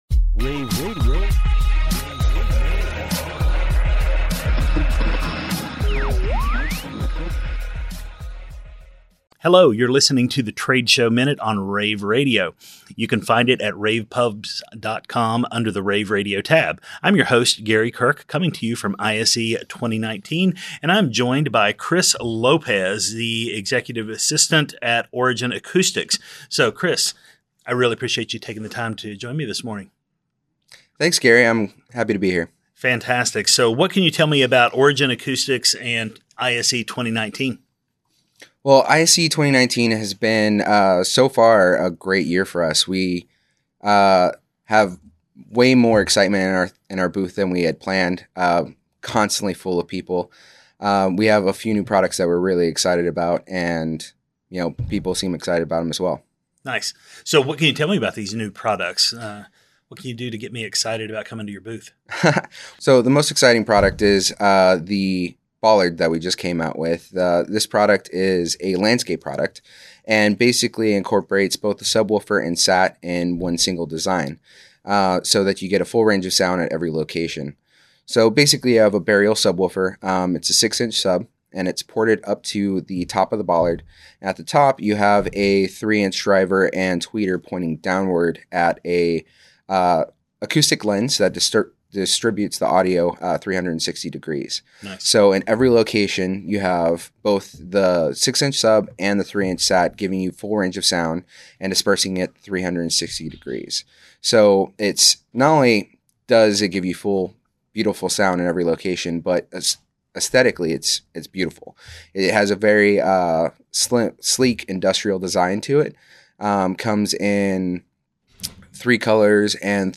February 6, 2019 - ISE, ISE Radio, Radio, rAVe [PUBS], The Trade Show Minute,